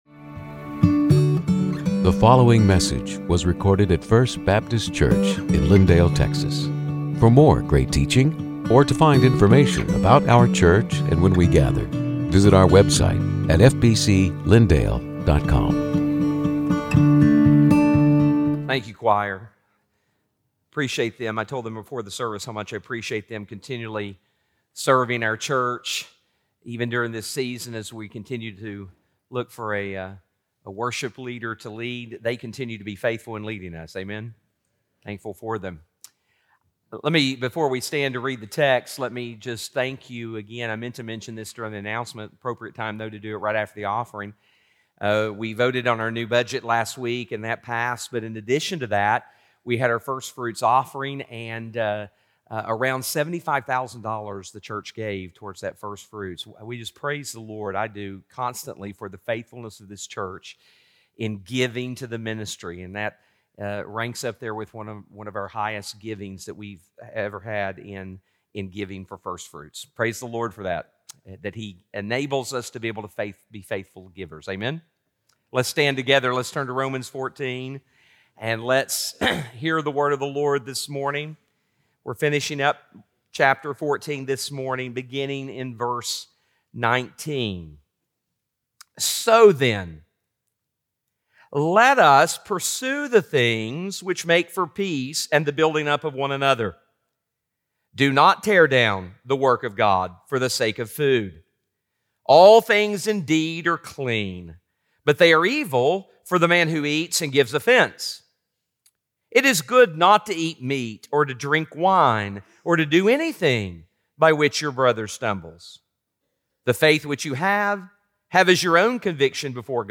Sermons › Romans 14:19-23